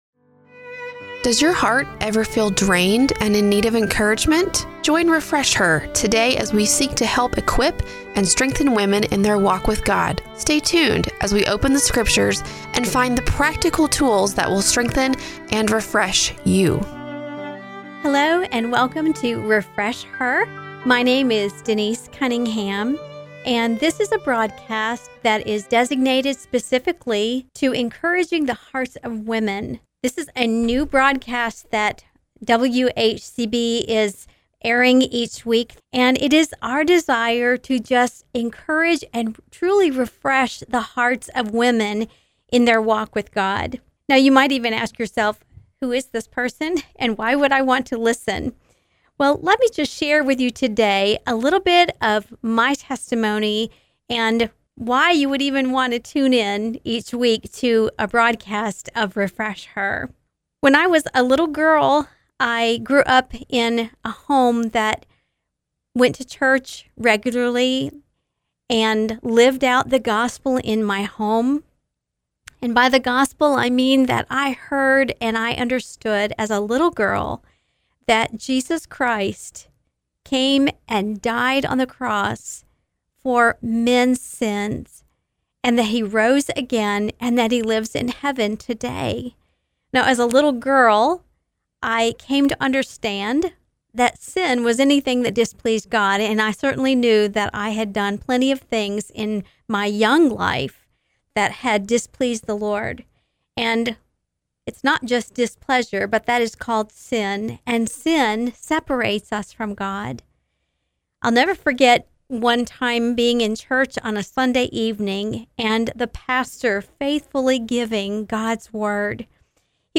As I close out this blogging week, I’m going to share with you the first broadcast from Refresh Her that aired on WHCB Radio last Saturday.